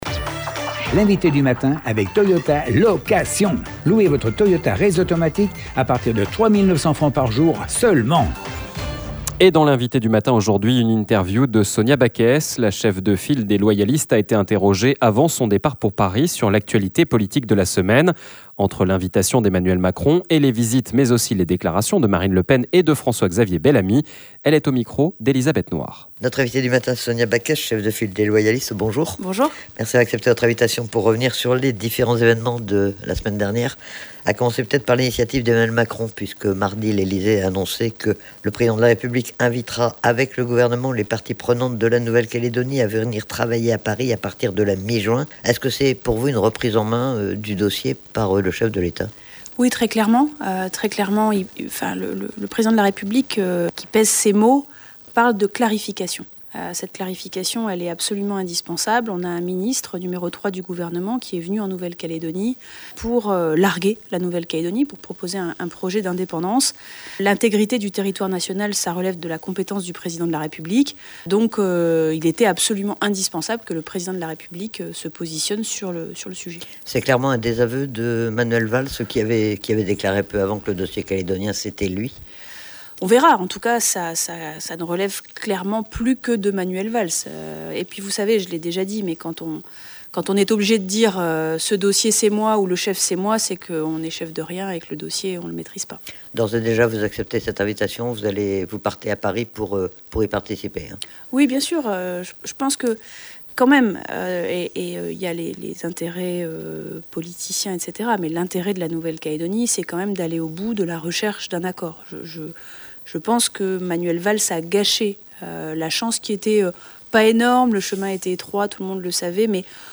Sonia Backès, la cheffe de file des Loyalistes, est notre invitée du matin, pour revenir sur l'actualité politique de la semaine : l'invitation d'Emmanuel Macron mais aussi les visites et les déclarations de Marine Le Pen et de François-Xavier Bellamy. Une interview réalisée avant le départ de Sonia Backès pour Paris, où elle aura de nombreux rendez-vous.